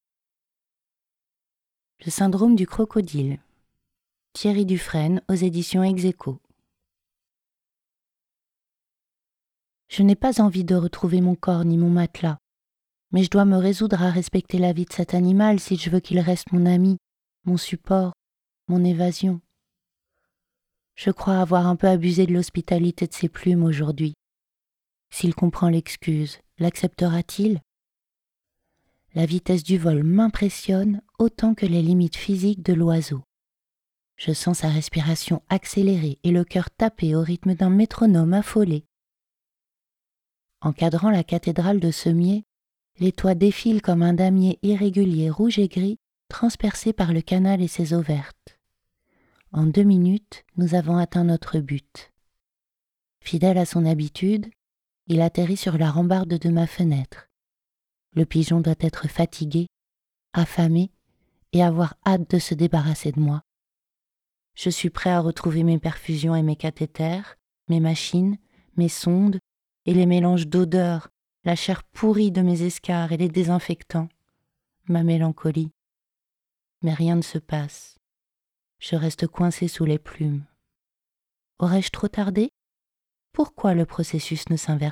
Fantastique / thriller — narration
Narration masculine, ambiance mystérieuse et immersive
demo-voix-le-syndrome-du-crocodile.mp3